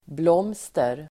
Ladda ner uttalet
blomster substantiv, flower Uttal: [bl'åm:ster] Böjningar: blomstret, blomster, blomstren Synonymer: blommor Definition: blomma Sammansättningar: blomster|handel (flower shop) floral adjektiv, blom- , blomster